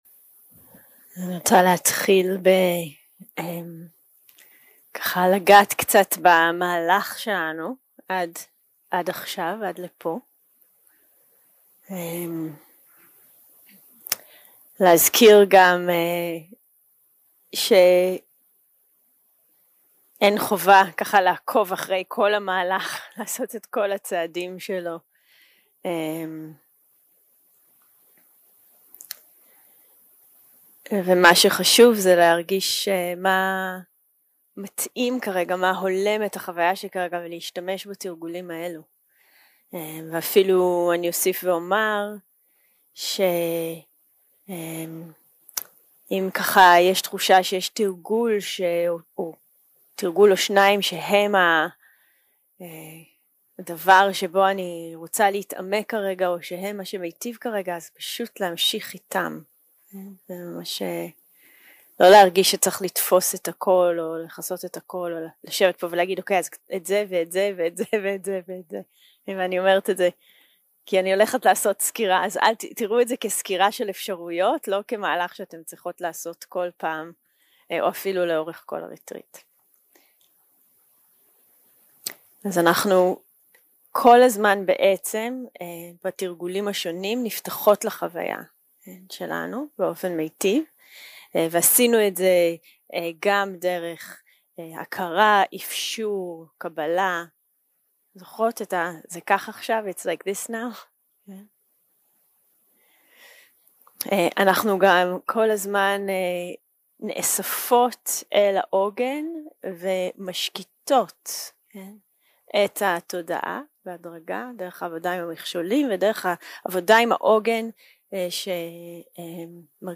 יום 6 – הקלטה 14 – בוקר – הנחיות למדיטציה - היזכרות בחיבור ביננו 0:00 0:00 סוג ההקלטה: Dharma type: Guided meditation שפת ההקלטה: Dharma talk language: Hebrew